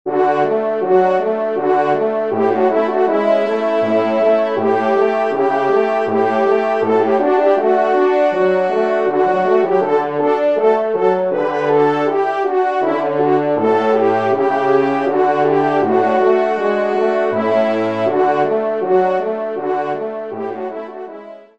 24 compositions pour Trio de Cors ou de Trompes de chasse